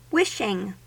Ääntäminen
Ääntäminen US : IPA : [ˈwɪʃ.ɪŋ] Haettu sana löytyi näillä lähdekielillä: englanti Wishing on sanan wish partisiipin preesens.